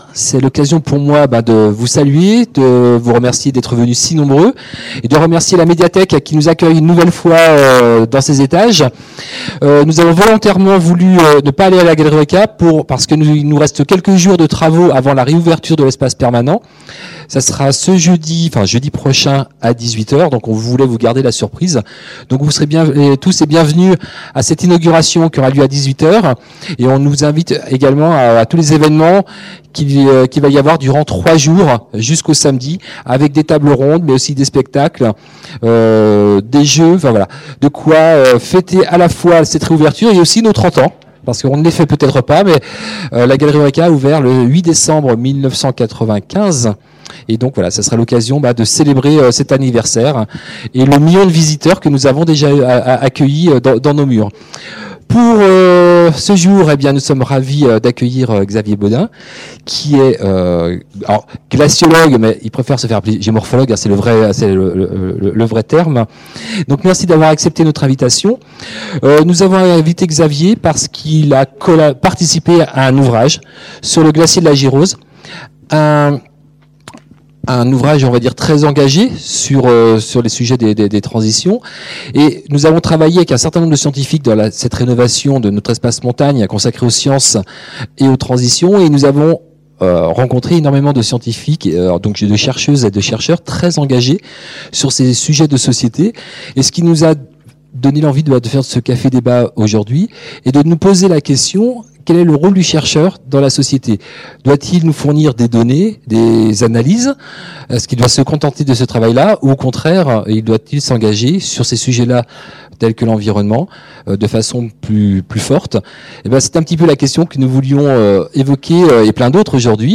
Une fois par mois, à Eurêka, venez rencontrer des spécialistes, poser vos questions et débattre avec eux lors des rendez-vous « Entre midi & science ».